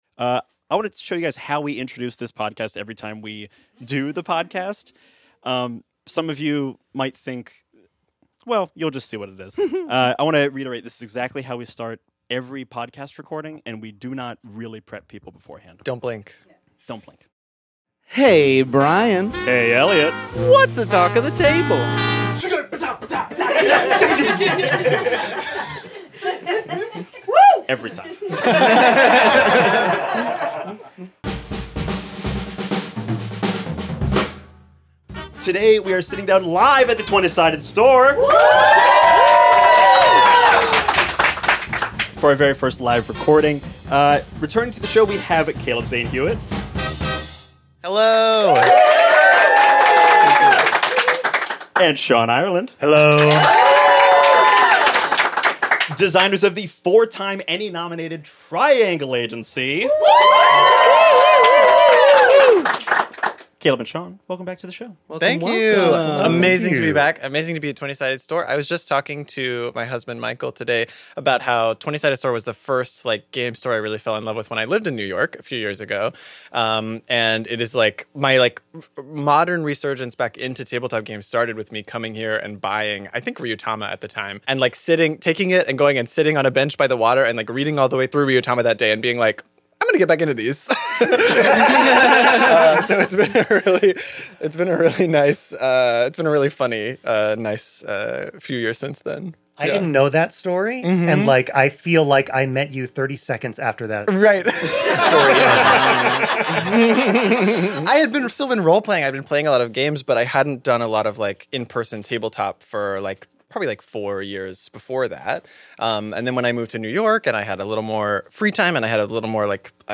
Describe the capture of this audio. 1 [LIVE SHOW] Triangle Agency